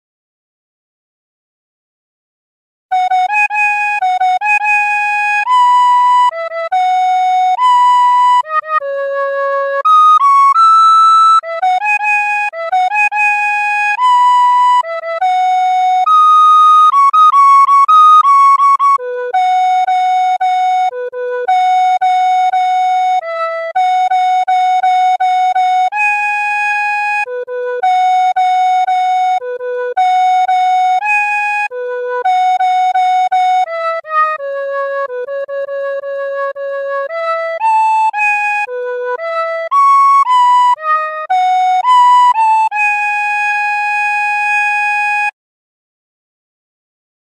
Flauta Dulce Cover